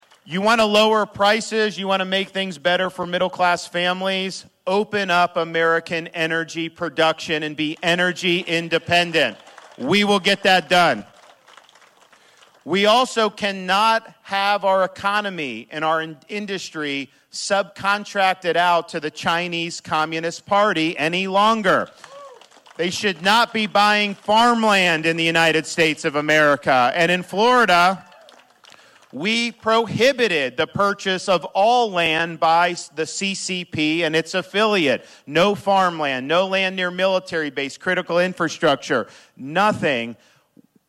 (Atlantic, IA) – Florida Governor Ron DeSantis spoke to Atlantic citizens today on multiple issues, one being a focus on the economy. DeSantis emphasized that the United States government is giving away the local energy and farming industries overseas, as he wants the money to go back into the middle class.